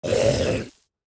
sounds / mob / husk / death1.ogg
death1.ogg